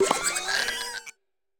Cri de Farigiraf dans Pokémon Écarlate et Violet.